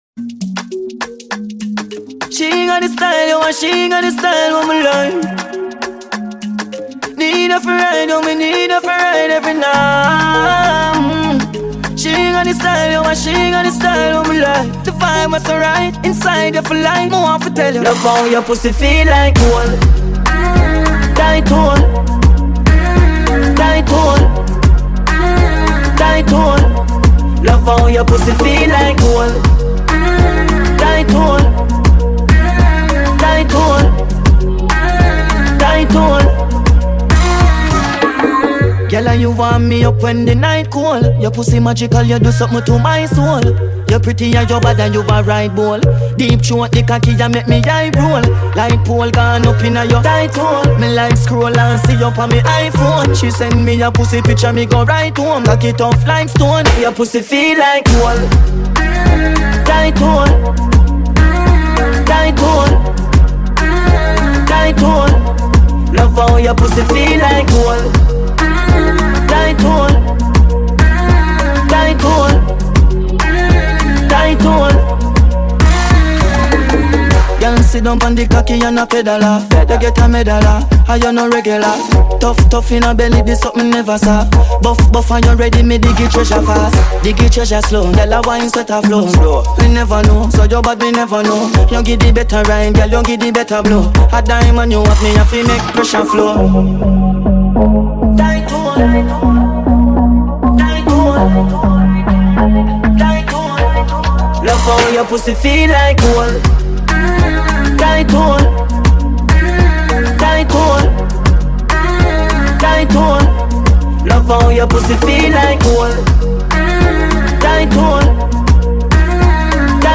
Jamaican dancehall